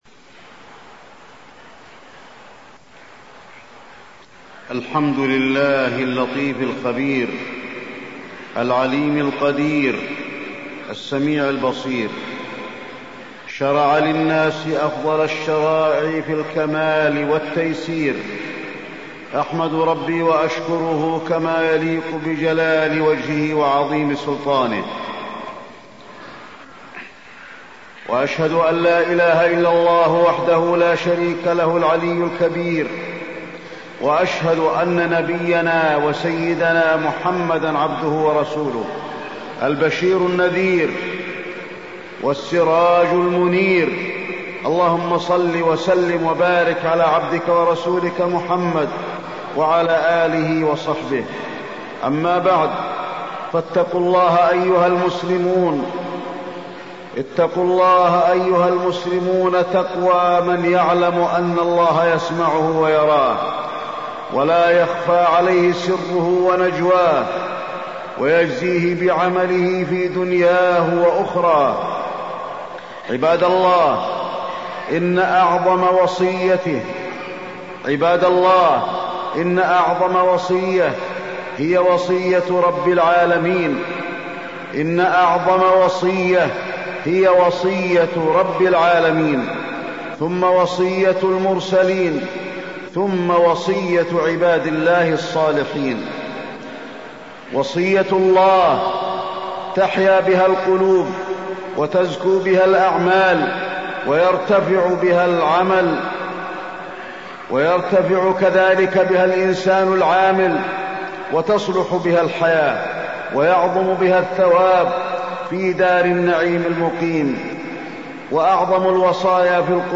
تاريخ النشر ٢٥ ربيع الأول ١٤٢٥ هـ المكان: المسجد النبوي الشيخ: فضيلة الشيخ د. علي بن عبدالرحمن الحذيفي فضيلة الشيخ د. علي بن عبدالرحمن الحذيفي الأحداث الأخيرة